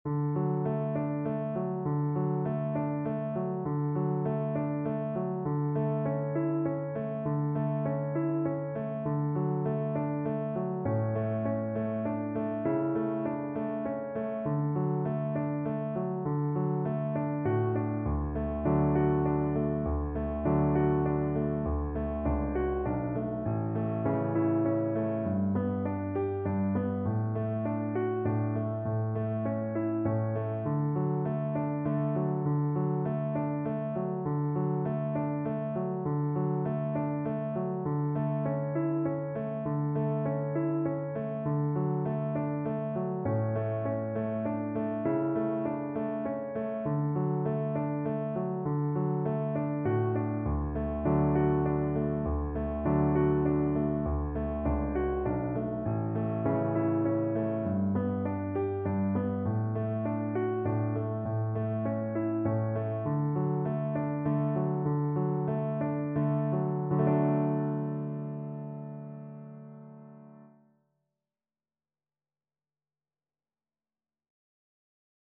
Traditional Music of unknown author.
3/4 (View more 3/4 Music)
Gently =c.100
Traditional (View more Traditional Viola Music)